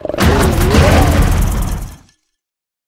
Cri de Koraidon dans sa forme Finale dans Pokémon HOME.